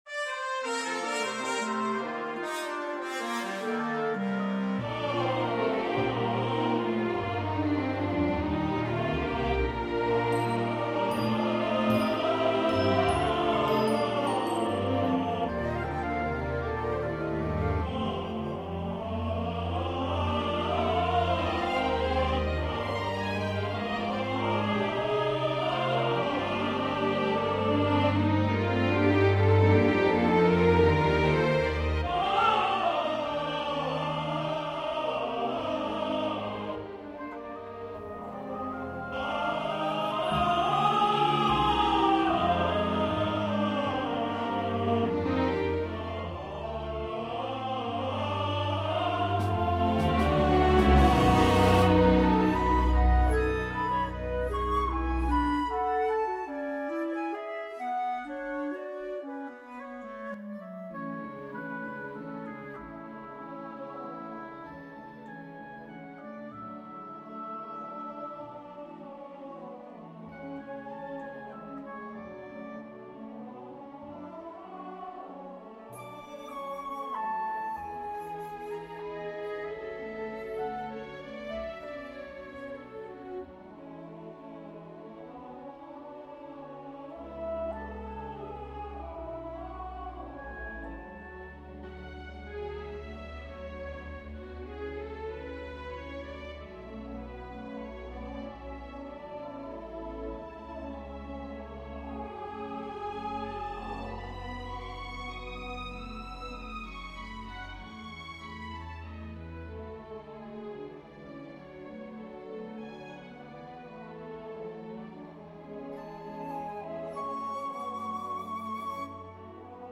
Aria for tenor, piano